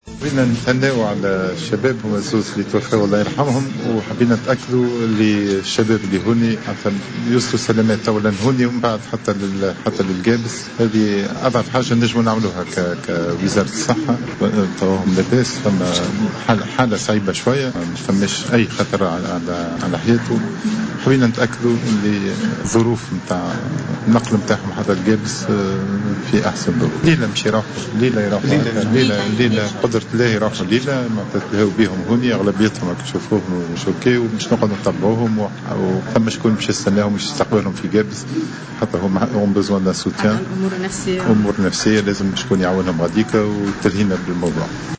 أكد وزيرُ الصحة سعيّد العايدي في تصريح للجوهرة أف أم عقب زيارته عشيّة اليوم الى المصابين من الحادث بالمستشفى الجامعي سهلول ، ان الحالة الصحية للمصابين مستقرة ولا خطر على حياة أغلبهم.